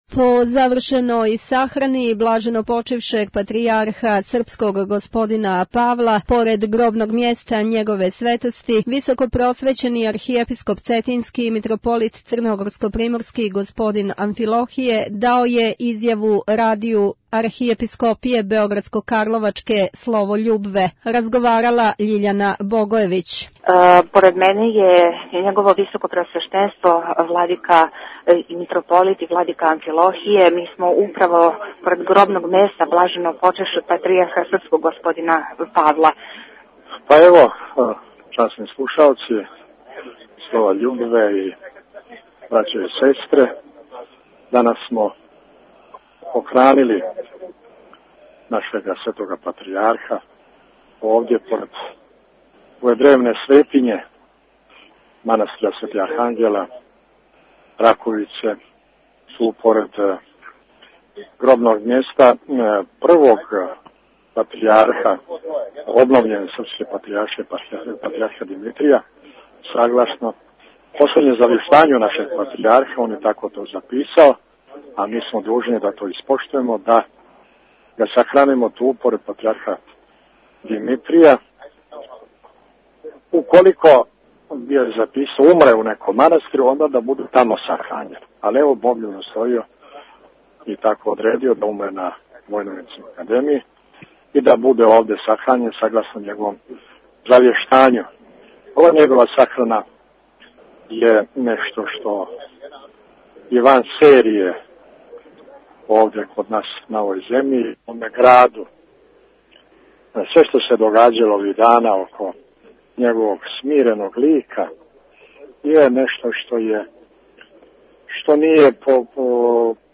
Изјава Архиепископа Г. Амфилохија и академика Матије Бећковића дата Радију "Слово љубве" након сахране Патријарха Павла Tagged: +Патријарх Павле 13:41 минута (2.35 МБ) Након сахране блаженопочћившег Свјатејшег Патријарха Српског Г. Павла у манастиру Раковица поред гробног мјеста Његове Светости изјаву за Радио Архиепископије Београдско - карловачек "Слово љубве" дао је Високопреосвећени Архиепископ Цетињски Мтрополит Црногорско - приморски Г. Амфилохије као и академик Матија Бећковић.